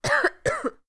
cough3.wav